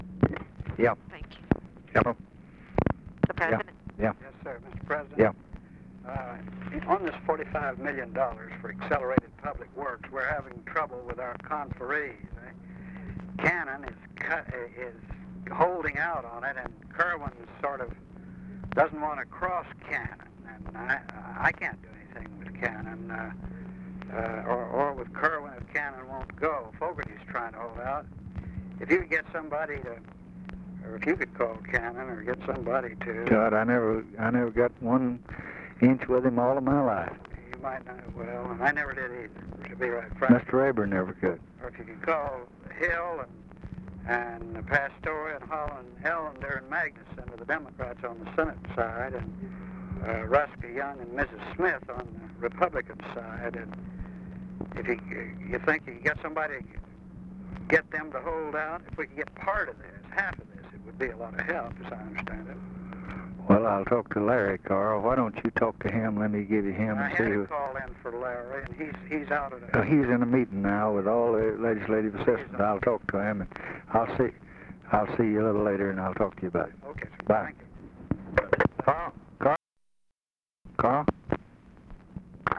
Conversation with CARL ALBERT, December 10, 1963
Secret White House Tapes